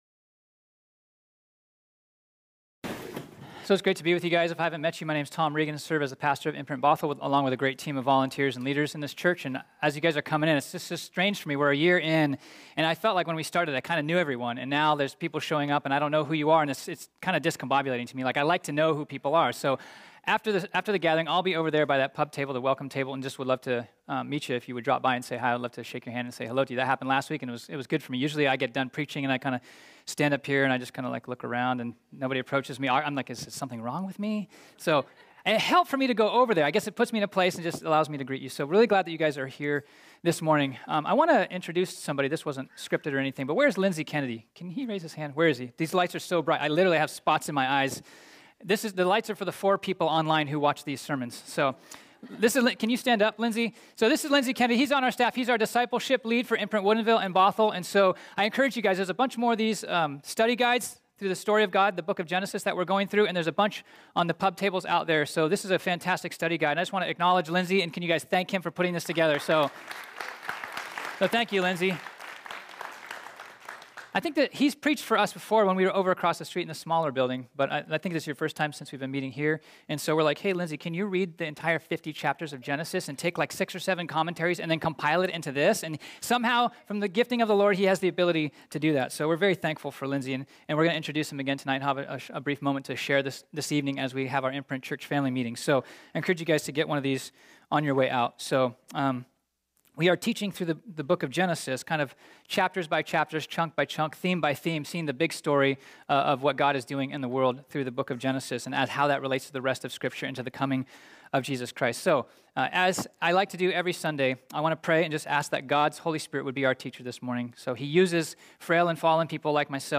This sermon was originally preached on Sunday, January 27, 2019.